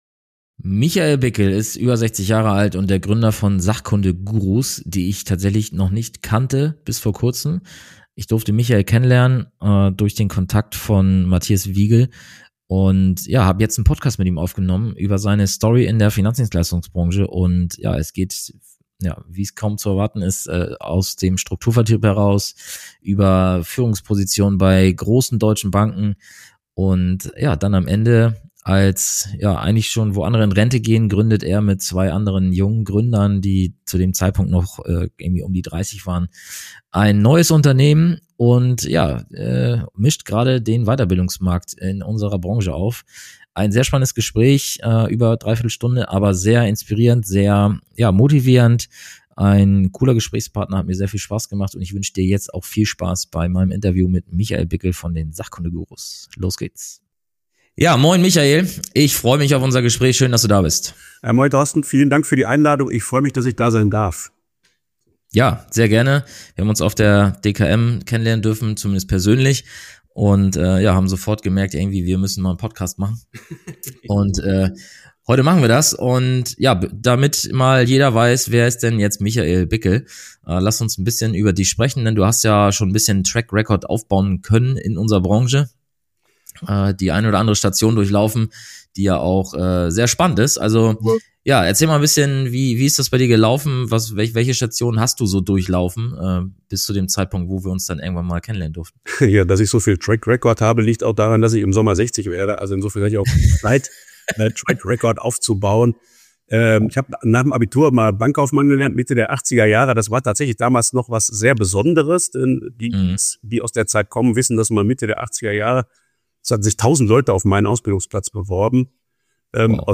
Herzlich willkommen zu einer neuen Folge des Makler und Vermittler Podcasts!